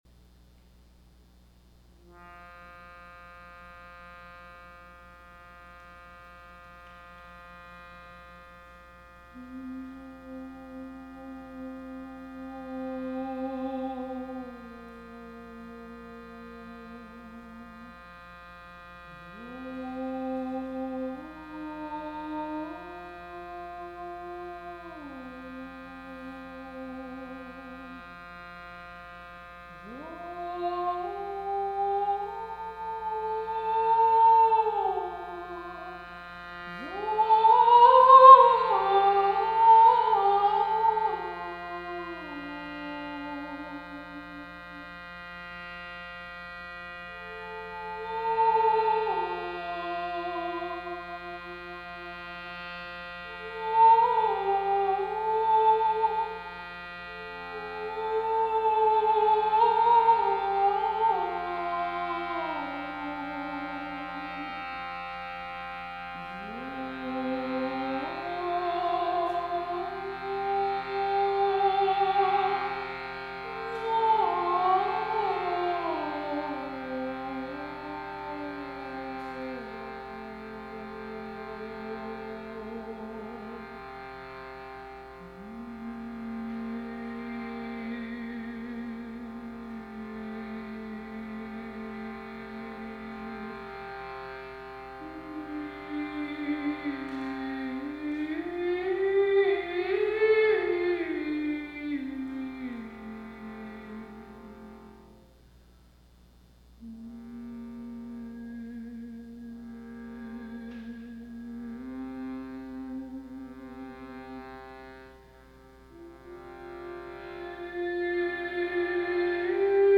A sound healing
schruti box (a devotional instrument of India)